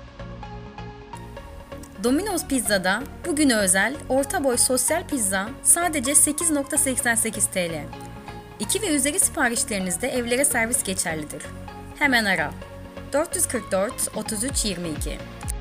Sesli Mesaj
BAYAN.wav